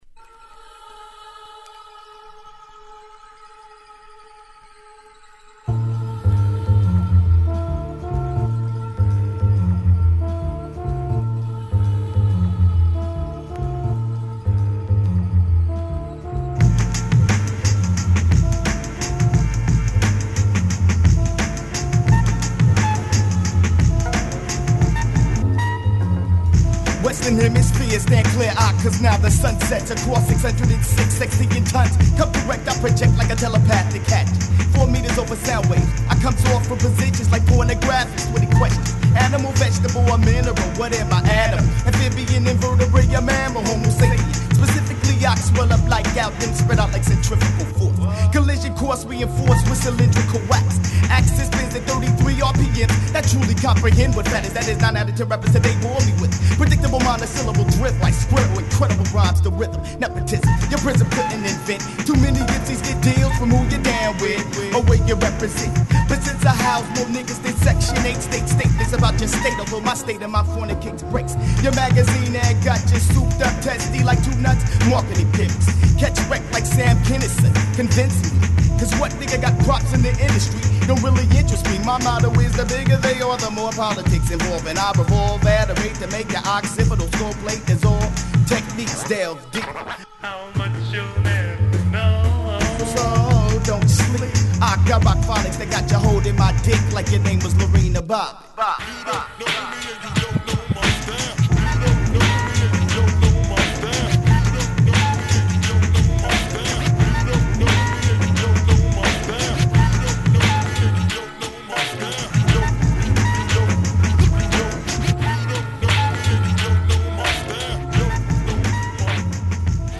ホーム HIP HOP UNDERGROUND 12' & LP R